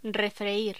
Locución: Refreír
voz
Sonidos: Voz humana